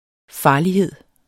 Udtale [ ˈfɑːliˌheðˀ ]